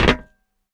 step2.wav